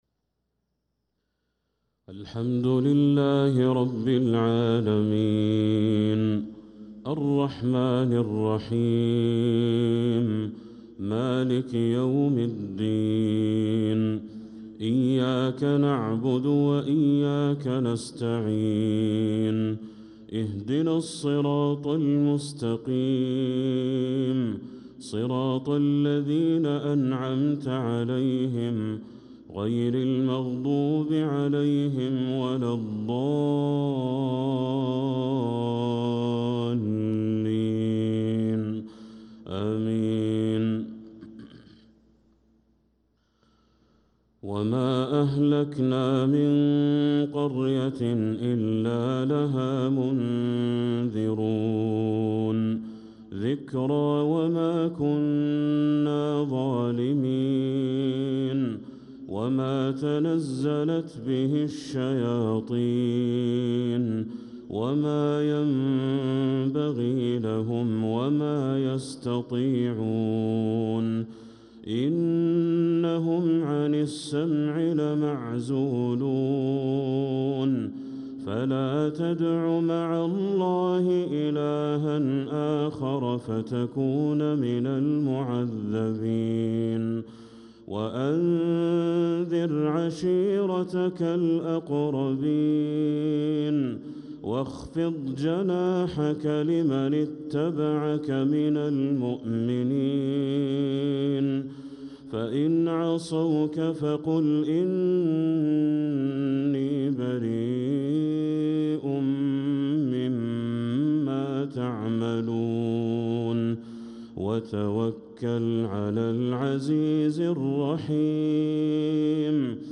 صلاة المغرب للقارئ بدر التركي 20 جمادي الأول 1446 هـ
تِلَاوَات الْحَرَمَيْن .